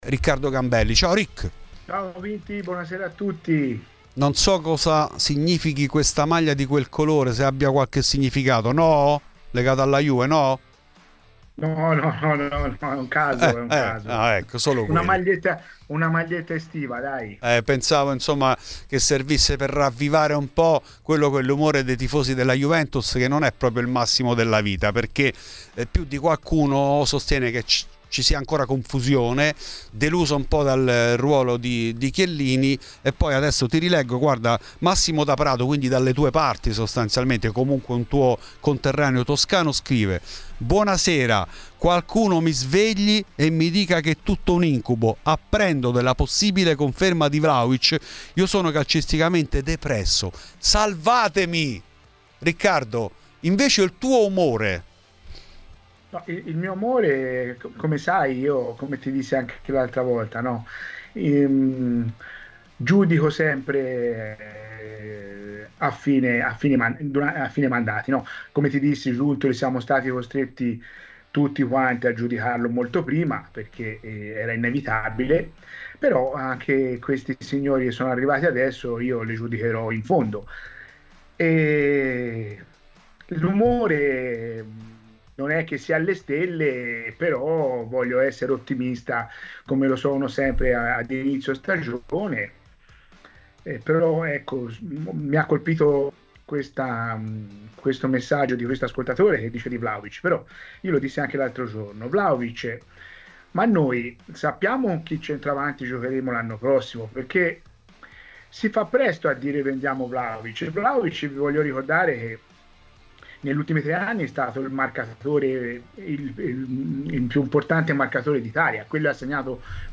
Di seguito, l'intervista integrale riportata da Bianconera News .